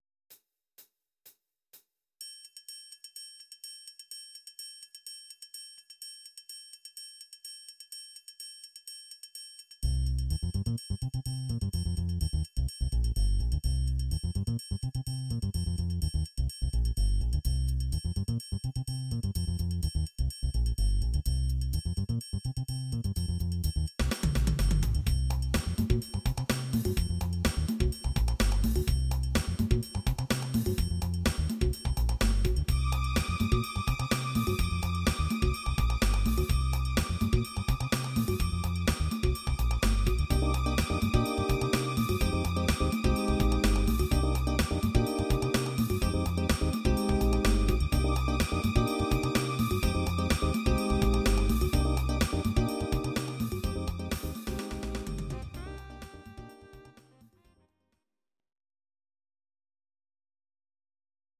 Audio Recordings based on Midi-files
Jazz/Big Band, 1970s